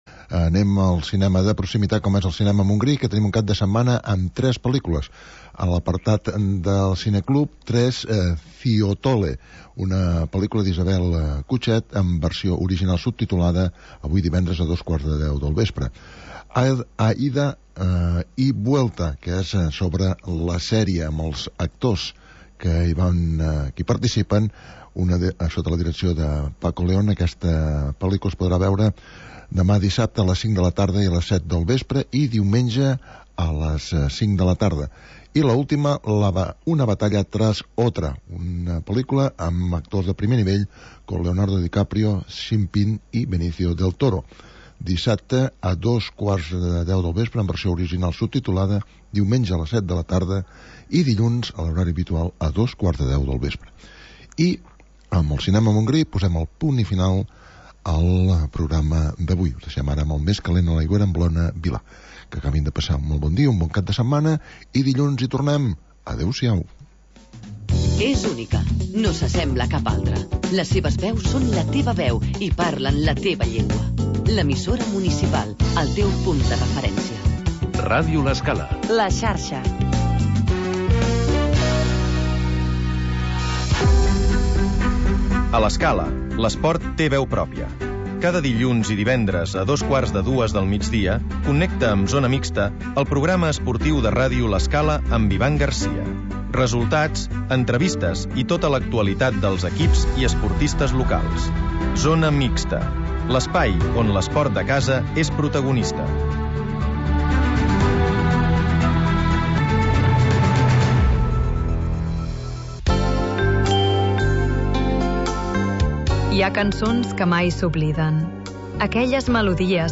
Magazín d'entreteniment per passar el migdia